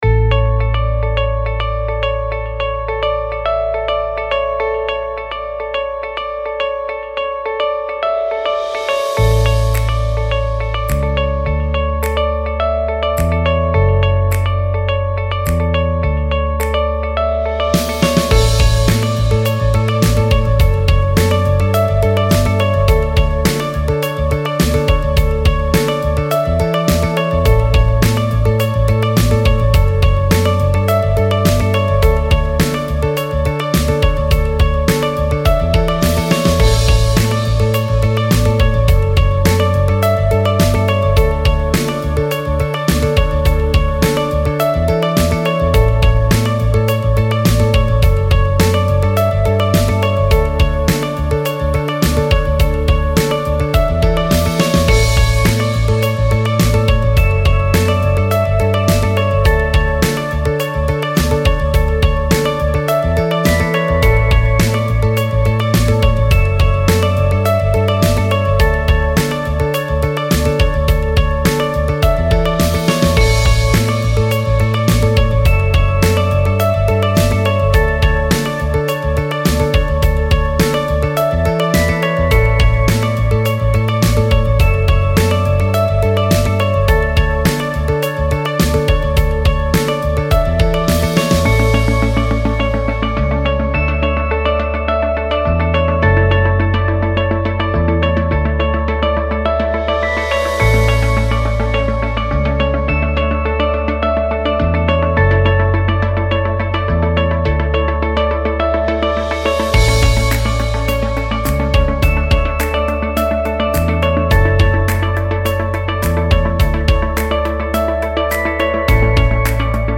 背景音乐为欢快元气活力韩风时尚视频音乐
该BGM音质清晰、流畅，源文件无声音水印干扰